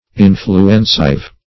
Search Result for " influencive" : The Collaborative International Dictionary of English v.0.48: Influencive \In"flu*en*cive\ ([i^]n"fl[-u]*en*s[i^]v), a. Tending to influence; influential.
influencive.mp3